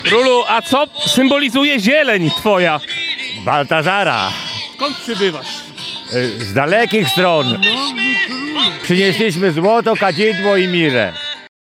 Baltazar w zielonych szatach reprezentował parafię pw. św. Andrzeja Boboli.